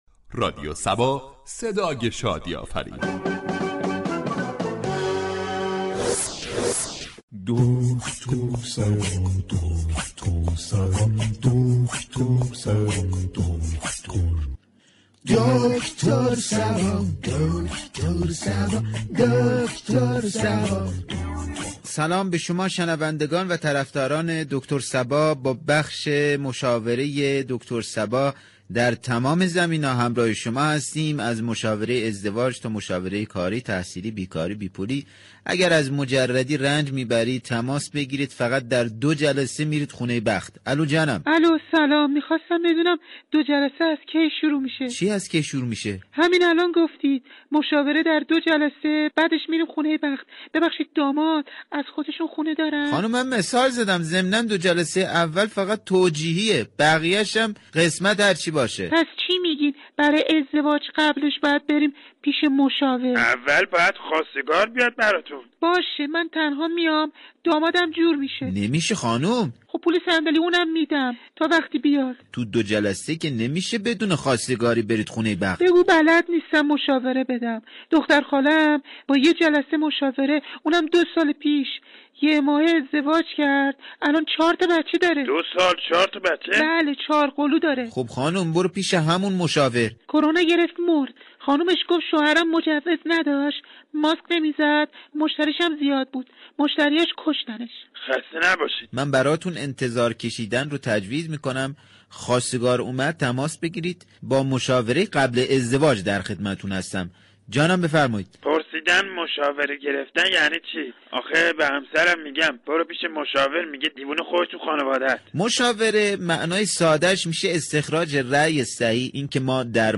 برنامه طنز "دكتر صبا " با بیان مسایل اجتماعی و فرهنگی با نگاهی طنز برای مخاطبان نسخه شادی و لبخند می پیچید.